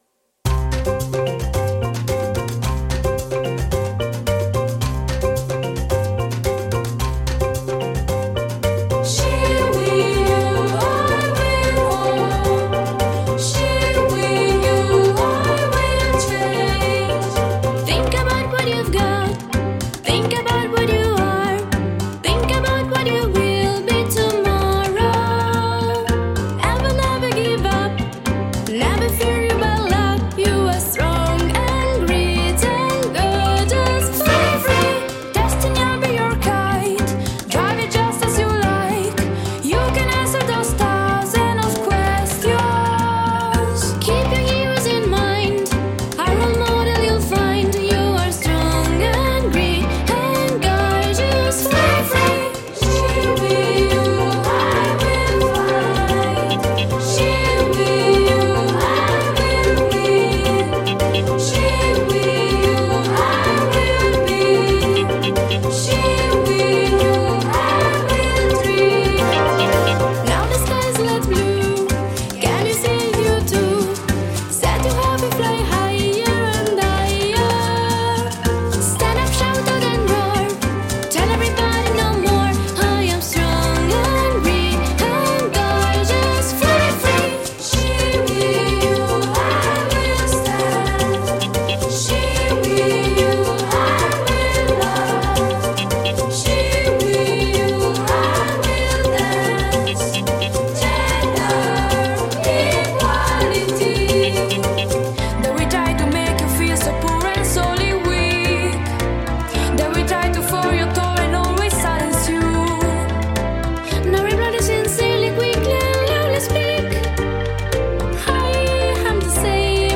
La seconda versione di “Equality” è frutto della collaborazione tra i laboratori musicali di Alessandria e Nairobi